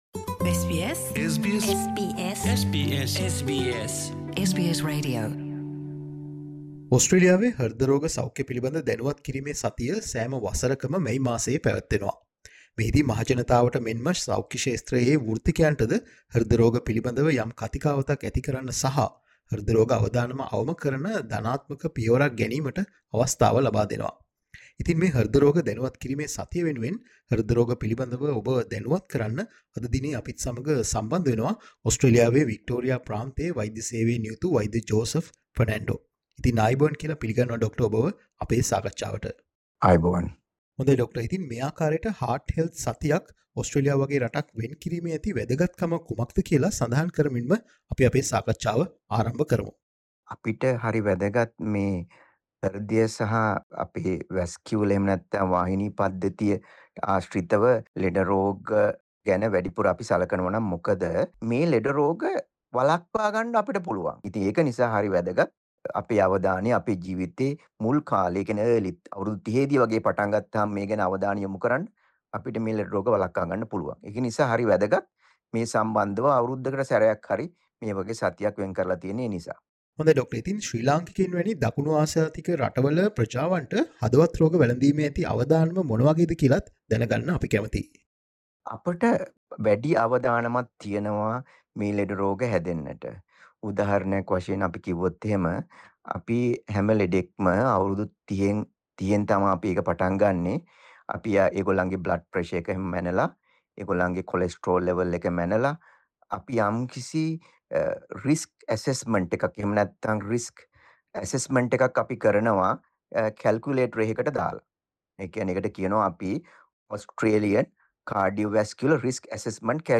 Listen to SBS Sinhala discussion for more information.